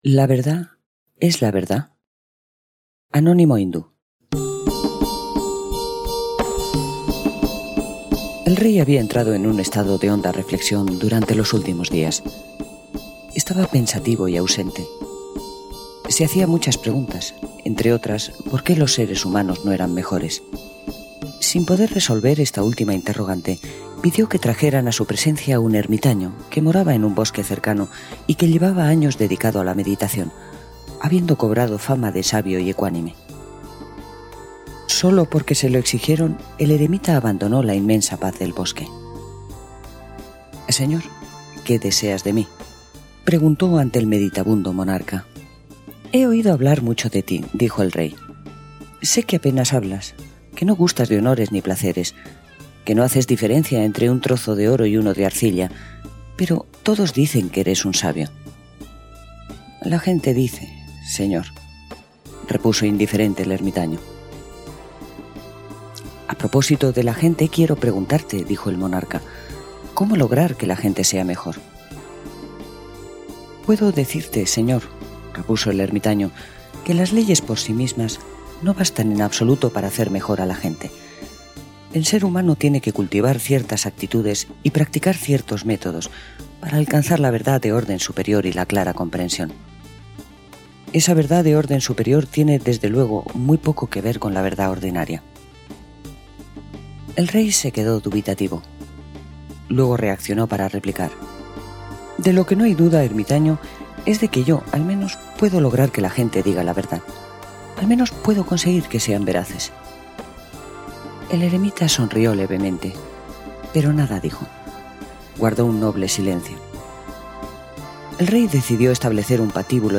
Audiolibro: La verdad... ¿es la verdad?
Cuento oriental